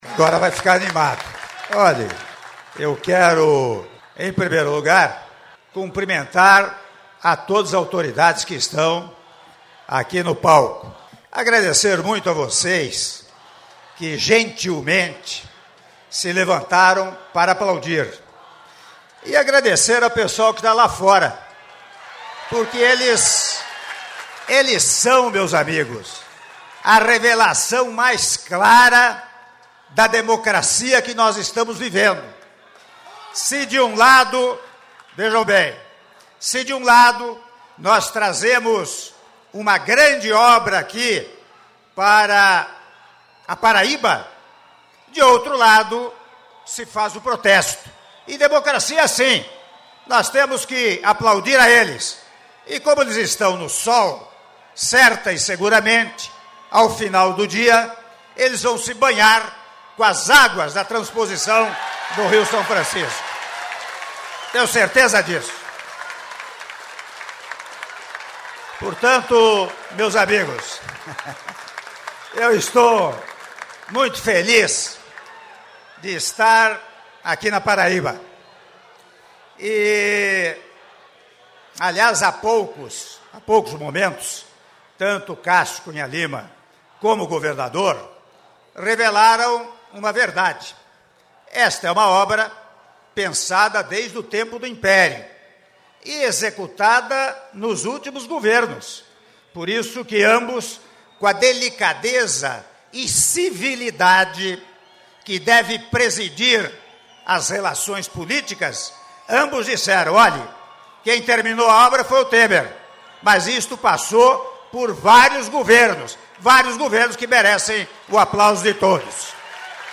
Áudio do discurso do Presidente da República, Michel Temer, durante cerimônia de chegada das Águas do Rio São Francisco à Paraíba - Monteiro/PB (07min45s)